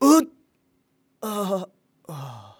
xys死亡2.wav 0:00.00 0:02.60 xys死亡2.wav WAV · 224 KB · 單聲道 (1ch) 下载文件 本站所有音效均采用 CC0 授权 ，可免费用于商业与个人项目，无需署名。